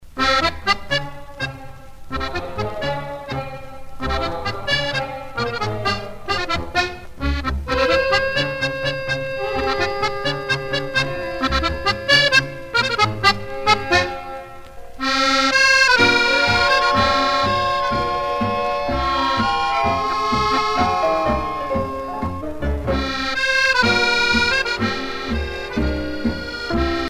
tango musette
Pièce musicale éditée